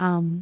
speech
cantonese
syllable
pronunciation
aam6.wav